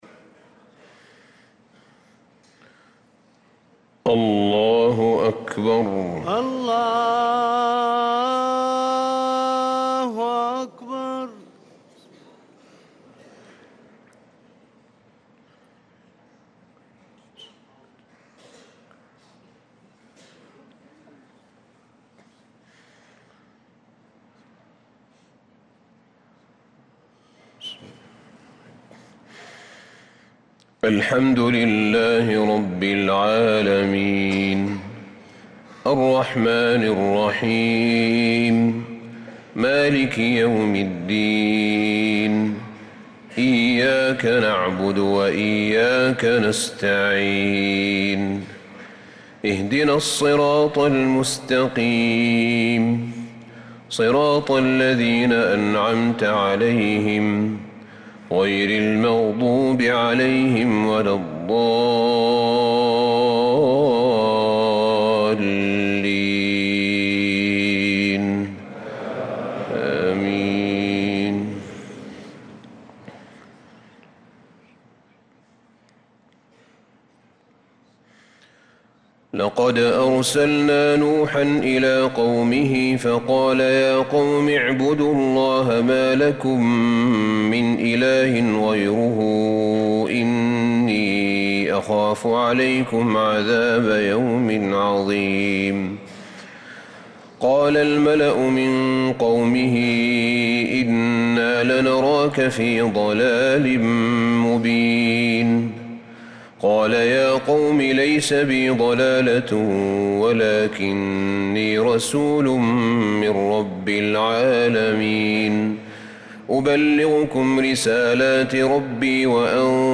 صلاة الفجر للقارئ أحمد بن طالب حميد 24 ذو الحجة 1445 هـ